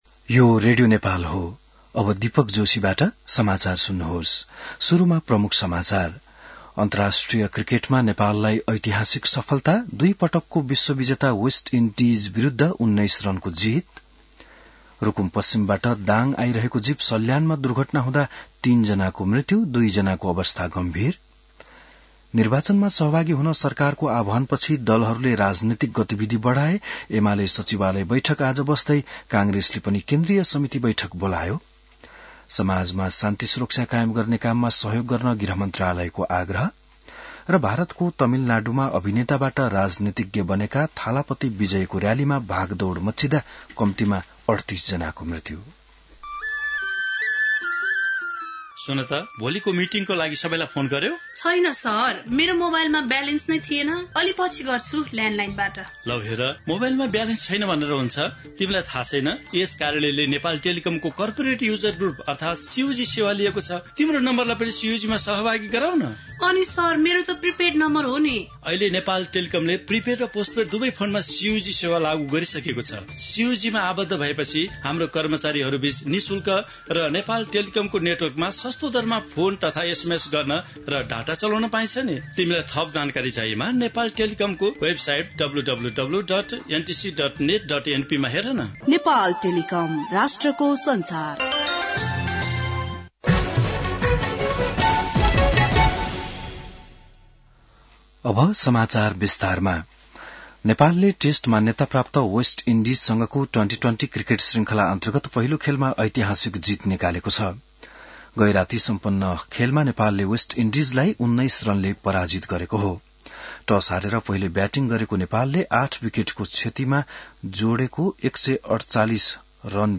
बिहान ७ बजेको नेपाली समाचार : १२ असोज , २०८२